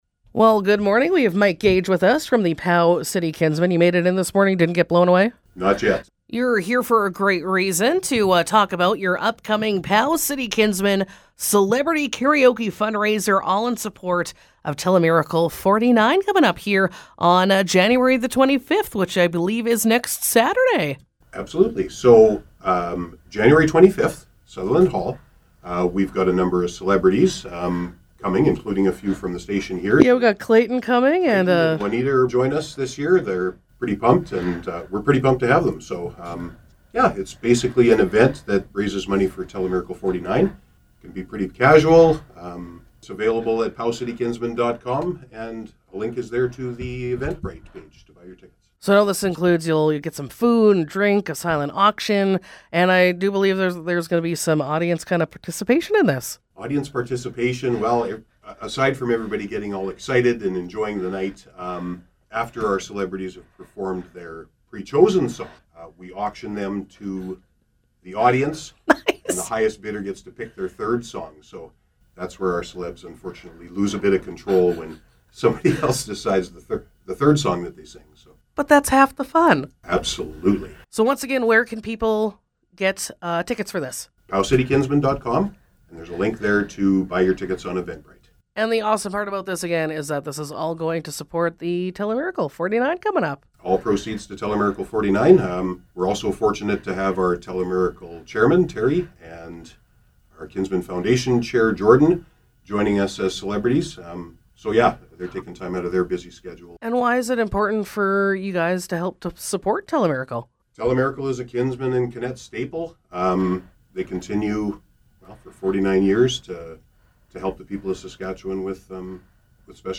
Interview: POW City Kinsmen Celebrity Karaoke for TeleMiracle 49